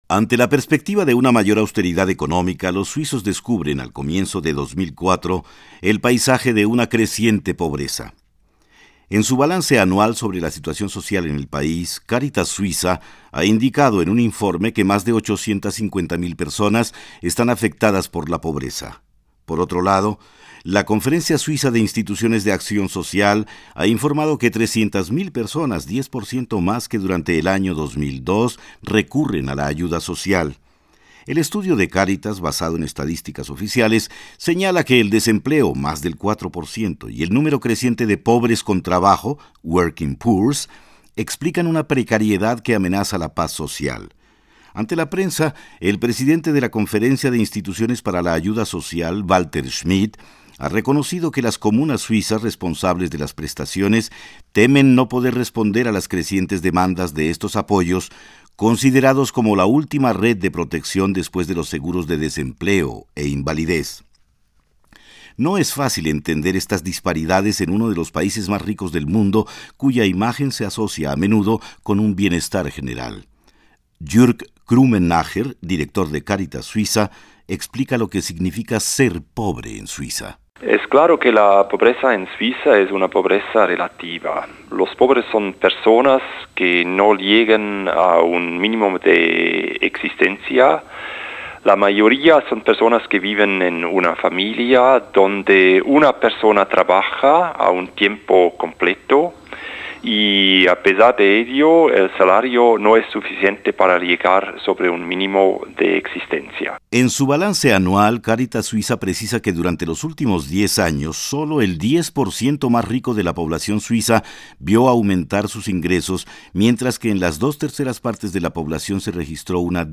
Más de 850.000 personas están afectadas por la precariedad y crece el número de asistidos sociales en uno de los países más ricos del mundo. Ante la perspectiva de una mayor austeridad económica, los suizos descubren el paisaje de una creciente pobreza. Un reportaje